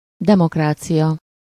Ääntäminen
Tuntematon aksentti: IPA: /ˈdɛmokraːt͡siʲɒ/